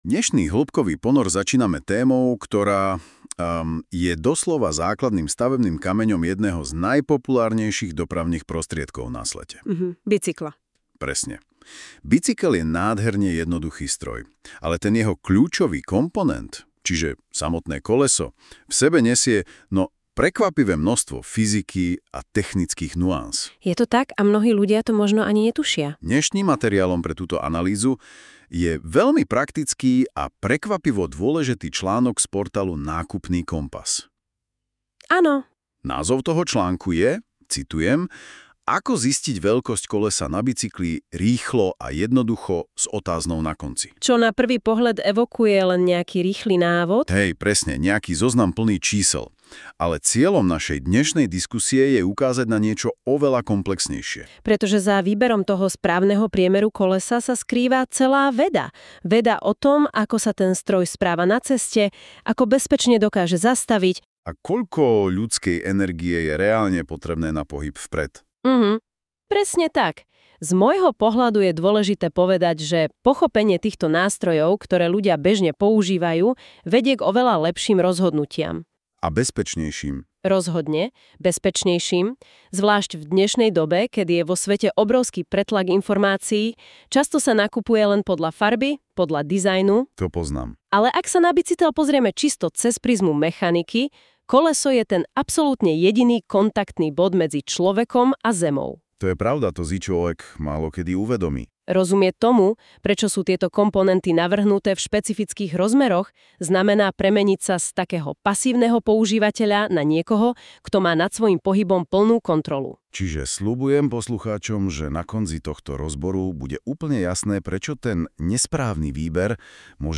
Vypočujte si dialóg a započúvajte sa do témy, ako zistiť veľkosť kolesa na bicykli a ďalšie užitočné informácie.